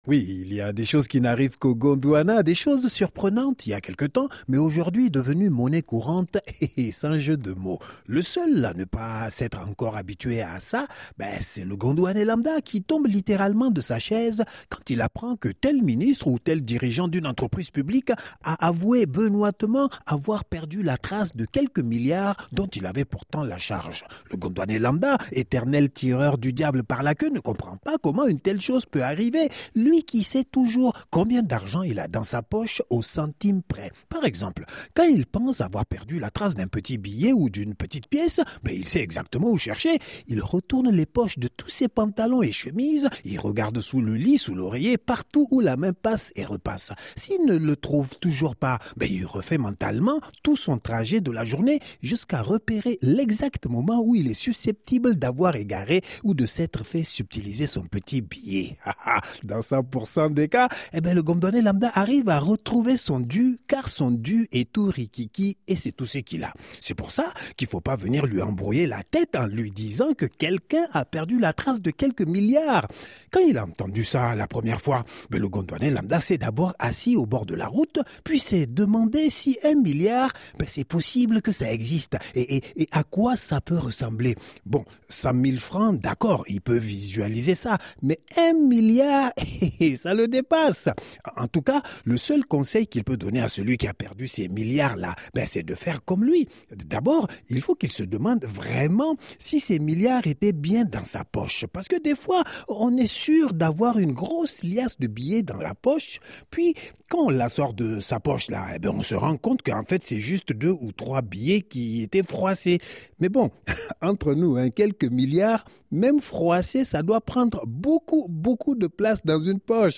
Il regarde l'actualité de biais, d'en haut, d'en bas, de côté ; il la retourne dans tous les sens, la soupèse et nous la rend complètement décalée, tordue et tellement tordante. Mamane vous présente une actualité... différente.
… continue reading 133 odcinków # France Médias Monde # Comédie pour toute la famille # Divertissement # Comédie # La COVID19 # Comédie de l'Actualité